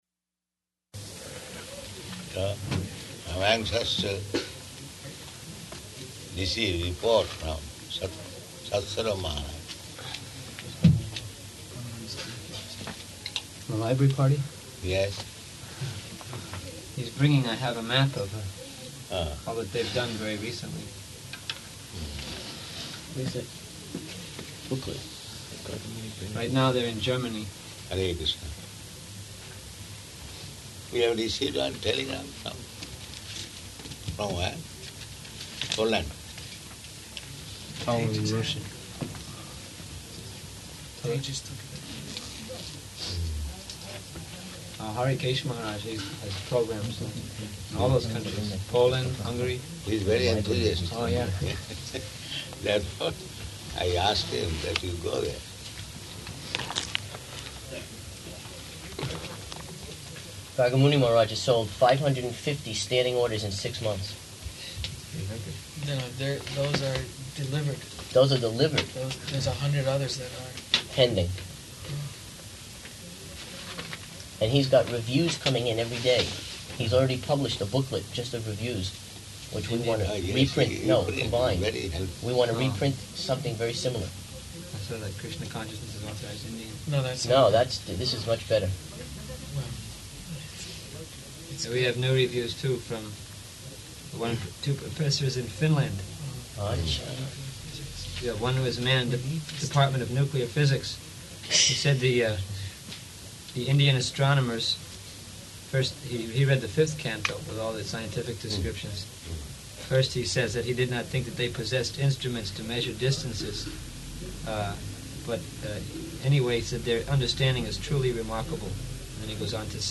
Room Conversation with Sannyāsīs
-- Type: Conversation Dated: January 22nd 1977 Location: Bhubaneswar Audio file